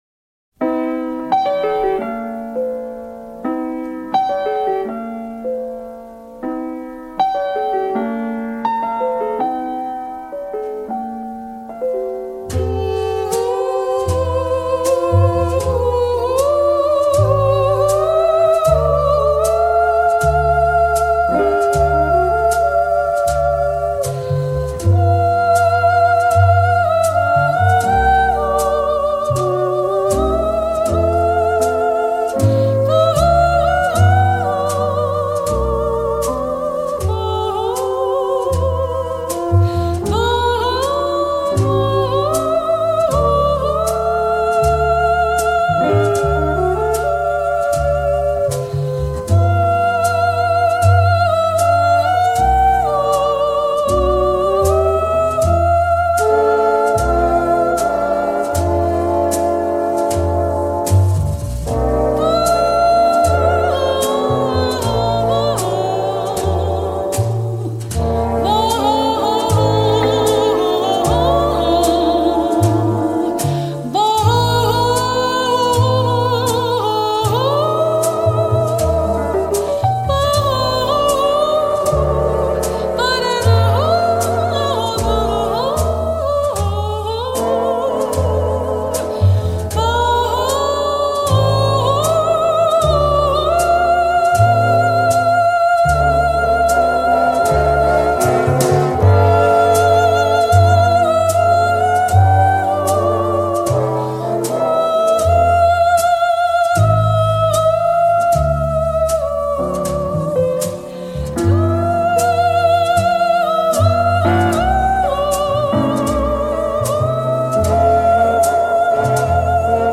jazz
” La seva veu abraçava tres octaves…